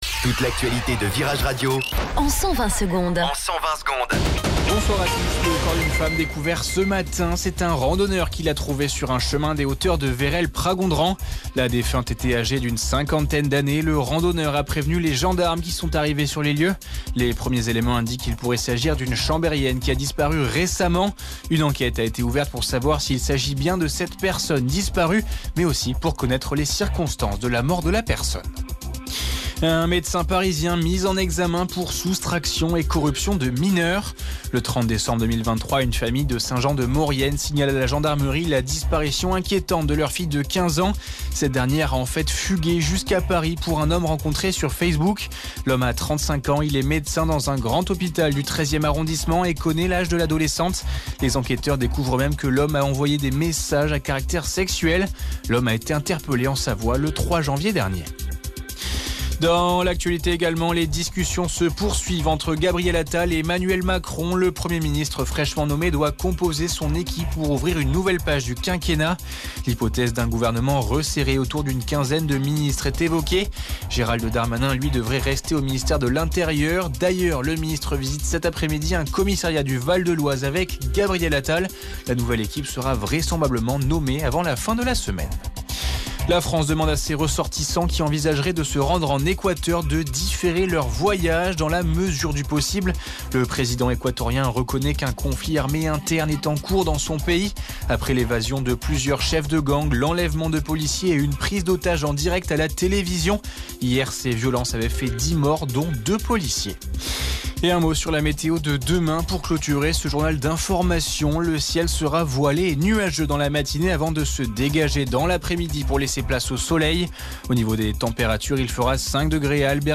Flash Info Chambéry